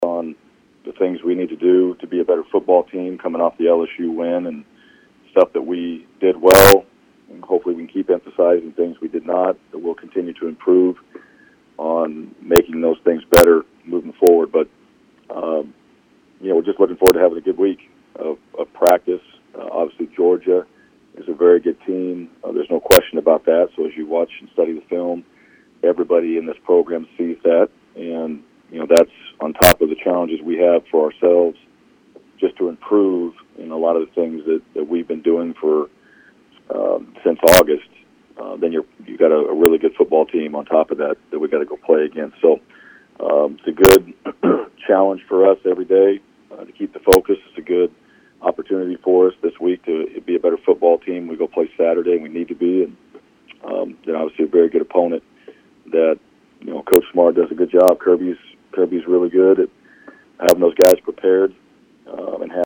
Tigers head coach Bryan Harsin talks about how the team has been practicing and preparing to enter Saturday’s game against Georgia.